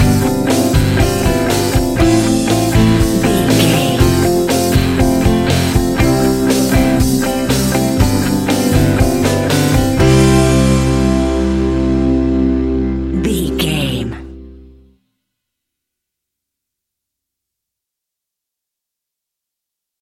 Ionian/Major
fun
energetic
uplifting
instrumentals
guitars
bass
drums
piano
organ